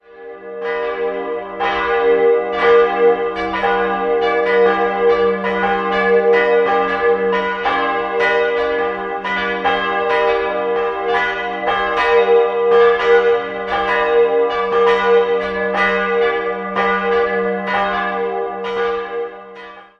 Sehenswert sind der Altar, die Kanzel und die Figur des Kirchenpatrons. 3-stimmiges Geläut: g'-h'-d'' Die große Glocke stammt vom Anfang des 16. Jahrhunderts, die mittlere wurde 1757 von Heroldt in Nürnberg und die kleinste 1922 von Heller in Rothenburg gegossen.